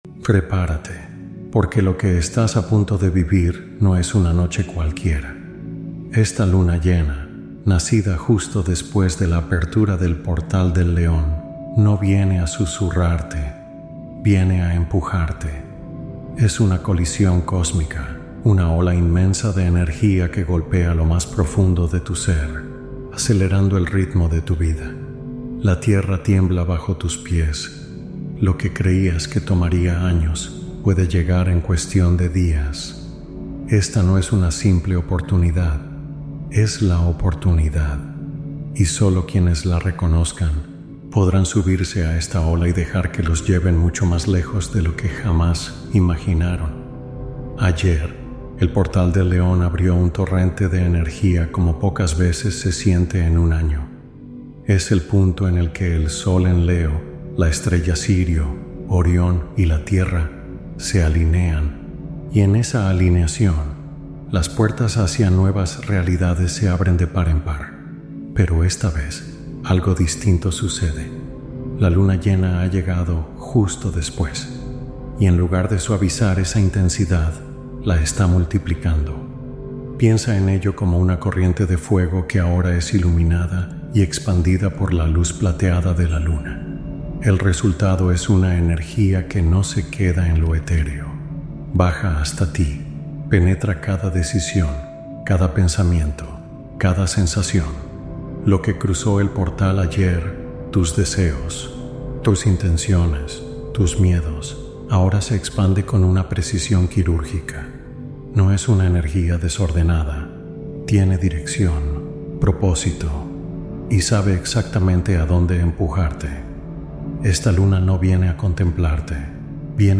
Meditación de umbral energético para procesos intensos de transformación